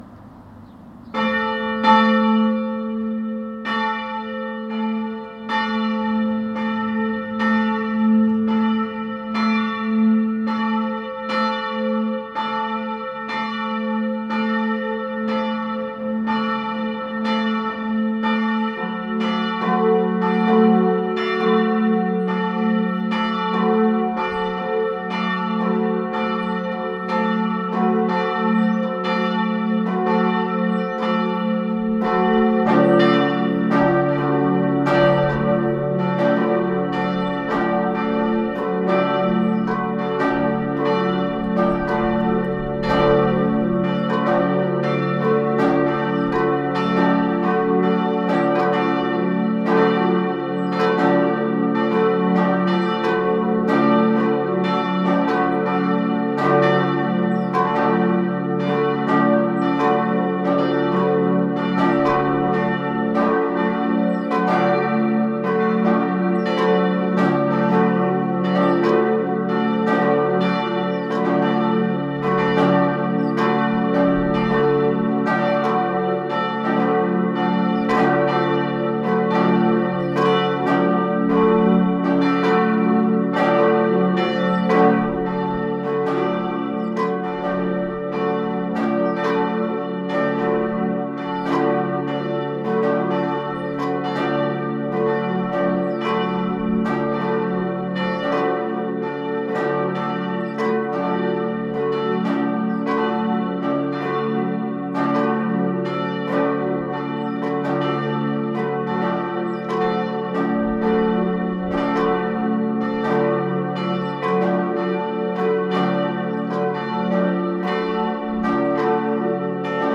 Die Bronzeglocken von 1702 waren in den Weltkriegen zu Granaten und Geschossen verarbeitet worden. 1922 konnten Eisenhartgussglocken erworben werden, die noch heute, nachdem sie ihre erwartete Lebensdauer schon überschritten haben, die Gemeinde zum Gottesdienst rufen. 2003 konnte eine dritte kleine Bronzeglocke gegossen werden.
Gegen Mittag am Samstag war es so weit: in präparierten Eimern wurden Handy und Aufnahmegerät auf einer Mauer platziert und auf die Schallluken des Kirchturms ausgerichtet.
Auch wieder eine Minute, dann folgt die größte Glocke.
Doch kaum ist es verklungen wird wieder die kleinste, mit 10 Sekunden Abstand die mittlere und weitere 10 Sekunden später die große Glocke für drei Minuten gestartet. Als die Tonaufnahme endlich im Kasten ist, erfolgt draußen ein Freudenjubel der Gruppe, ganz am Ende der Aufnahme.